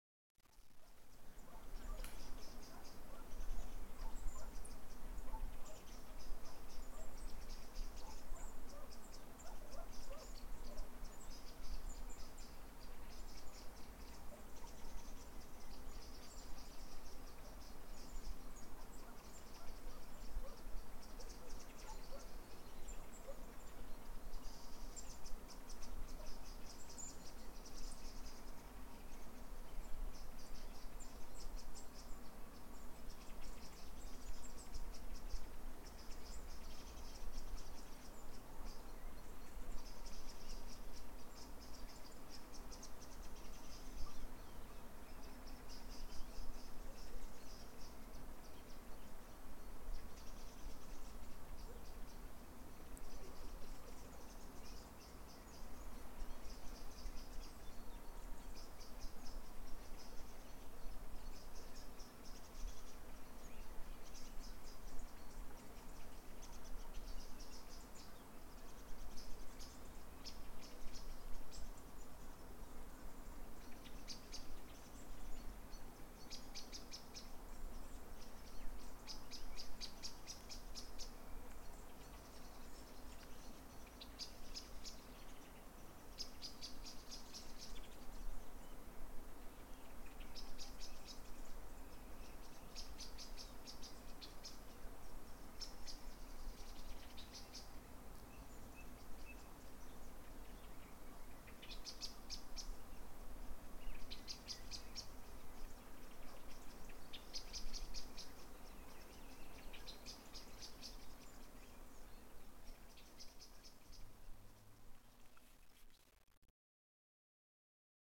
Birdsong in Stagiates, Greece